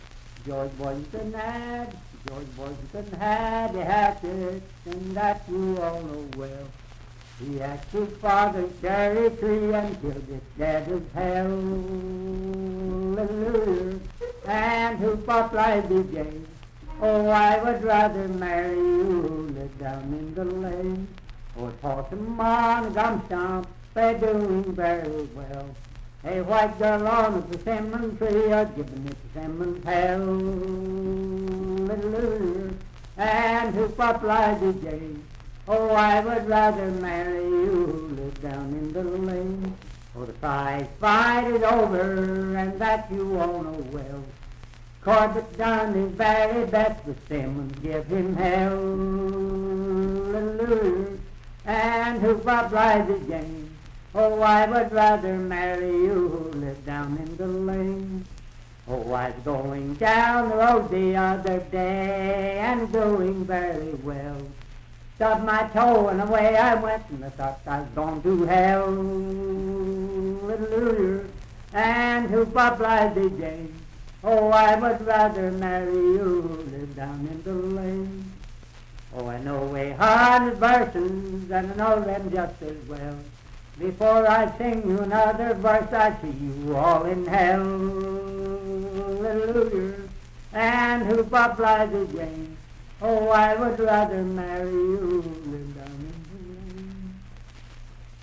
Unaccompanied vocal music and folktales
Voice (sung)
Parkersburg (W. Va.), Wood County (W. Va.)